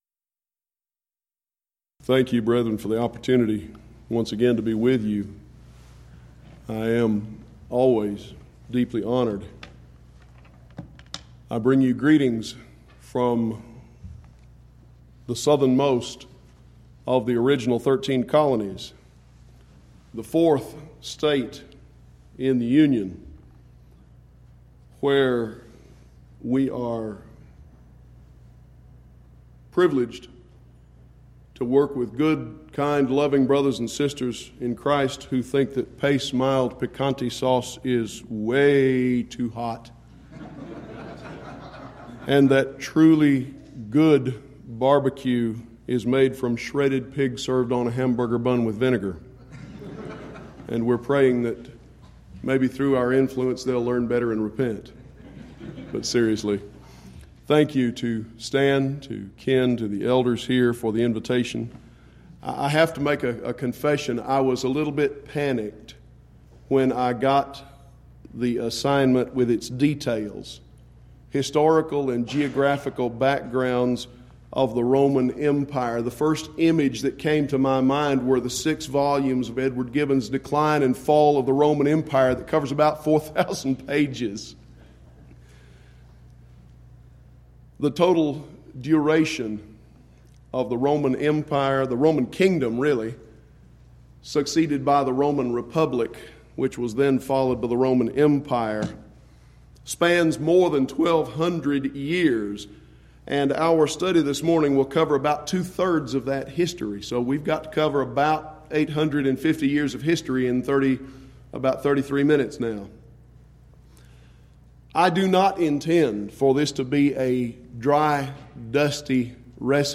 Event: 11th Annual Schertz Lectures Theme/Title: Studies in Daniel
lecture